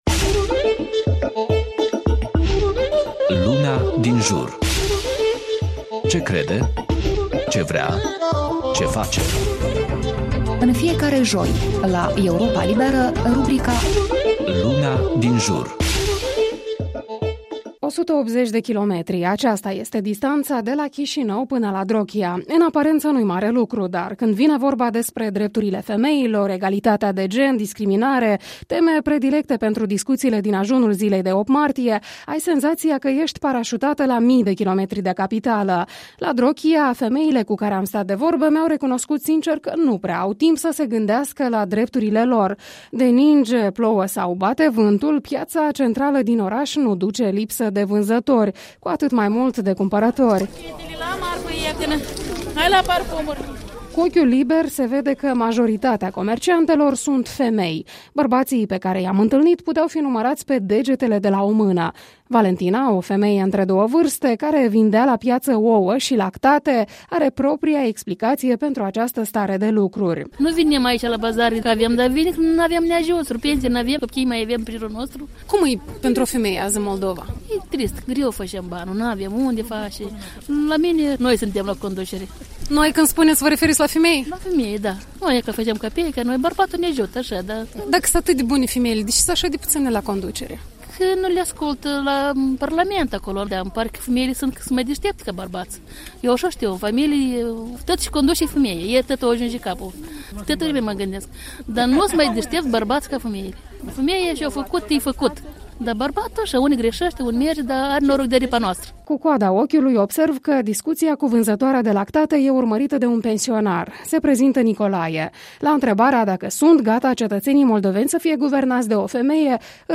Un reportaj despre drepturile femeii la piaţa din Drochia.